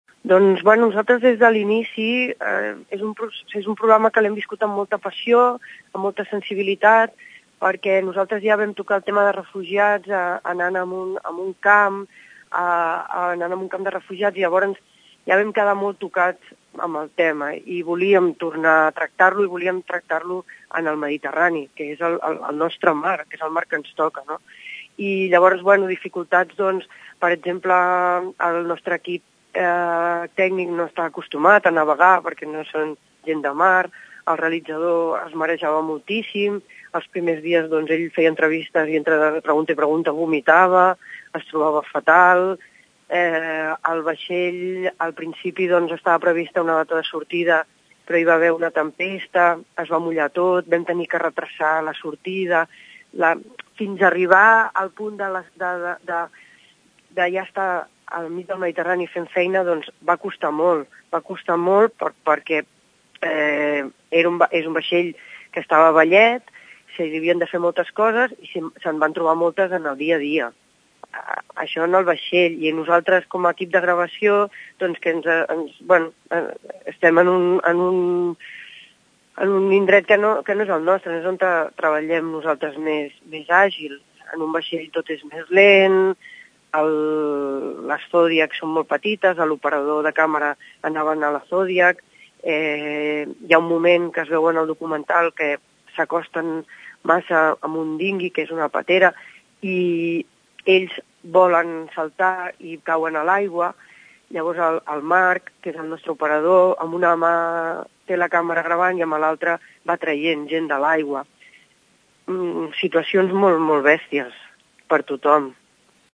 Ho explica en declaracions a Ràdio Tordera